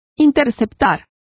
Complete with pronunciation of the translation of intercept.